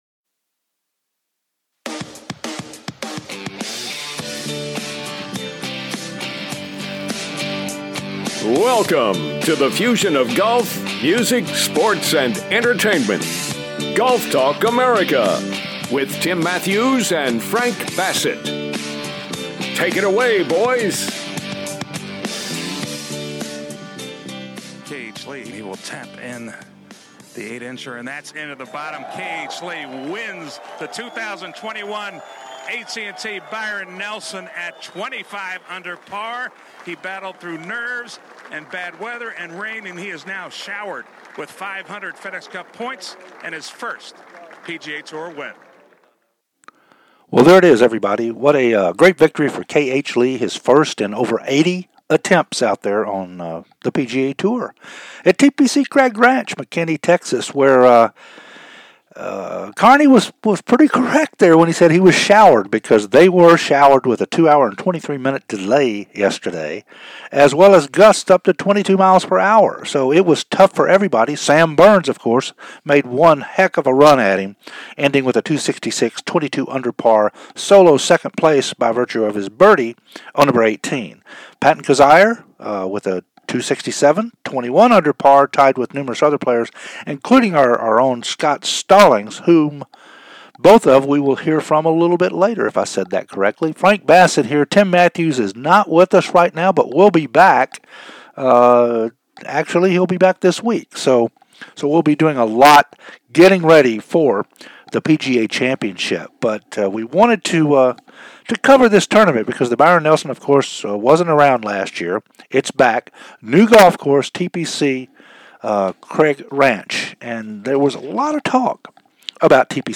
Interviews with Byron Nelson Champion, K H Lee plus Patton Kizzire and Scott Stallings